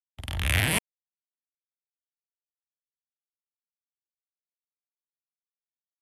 household
Zipper 11